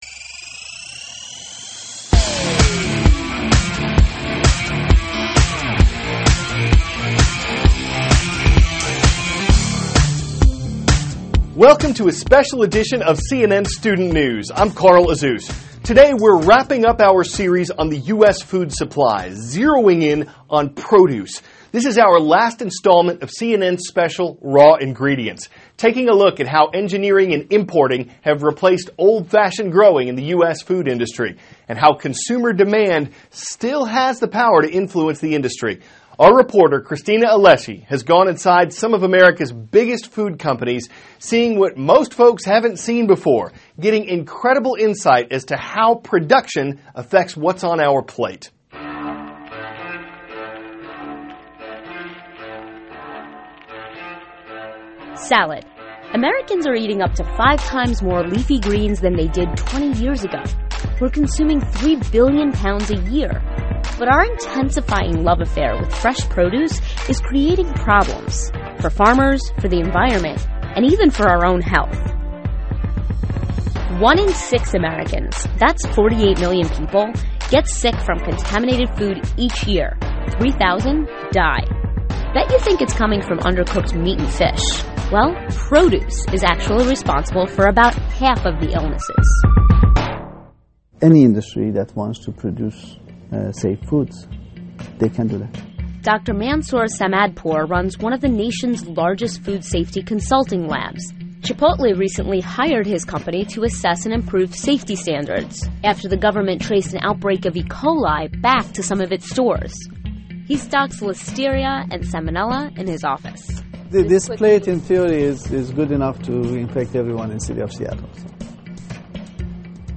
CARL AZUZ, CNN STUDENT NEWS ANCHOR: Welcome to a special edition of CNN STUDENT NEWS.